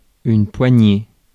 Ääntäminen
IPA : /hɪlt/